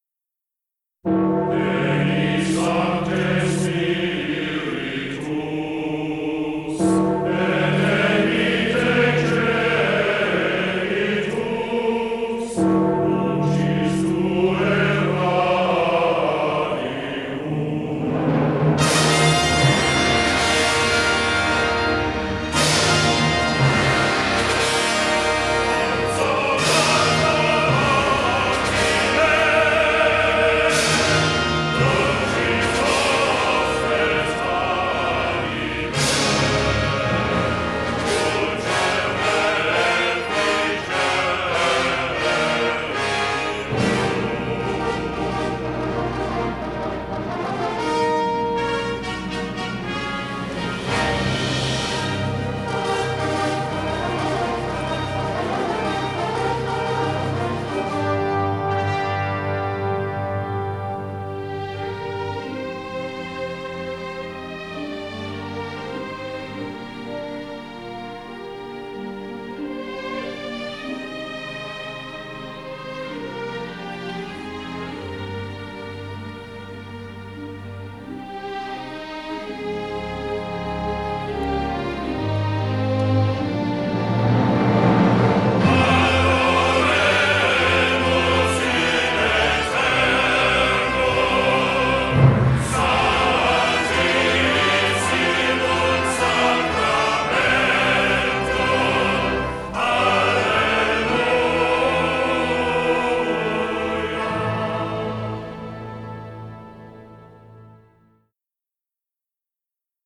Score recorded at Shepperton Studios in England